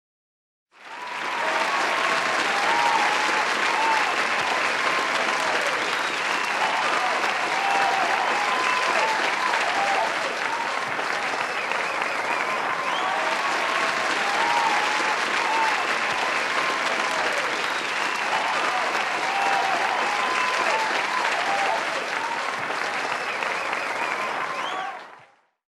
Applause